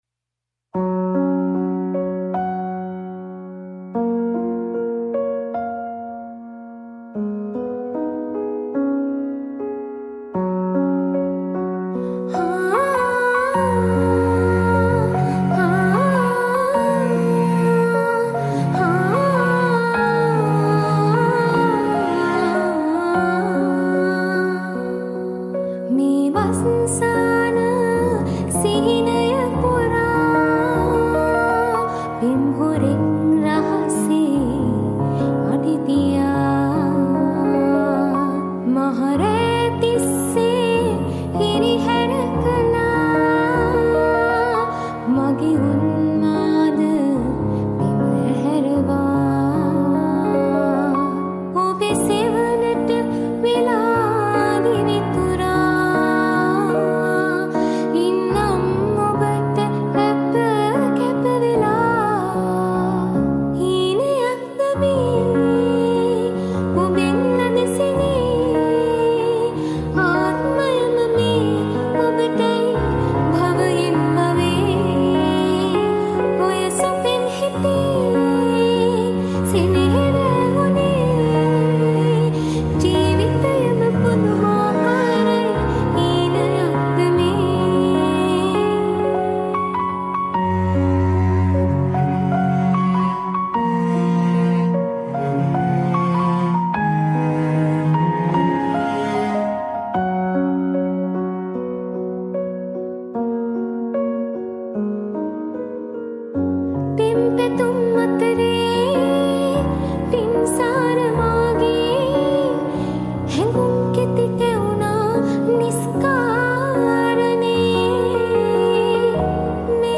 Cover vocal
Piano